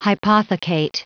Prononciation du mot hypothecate en anglais (fichier audio)
Prononciation du mot : hypothecate